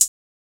Closed Hats
Sizzle Hat (HAT).wav